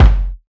Storch Kick 1.wav